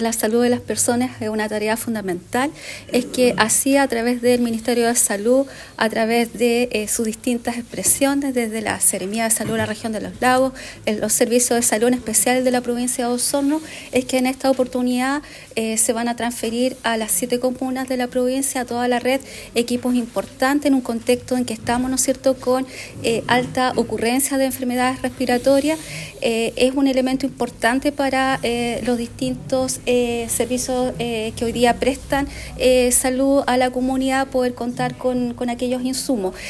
Del mismo modo, la Delegada Presidencial Provincial de Osorno, Claudia Pailalef destacó la importancia de la inversión que se realiza en materia de salud, para cuidar a la población local.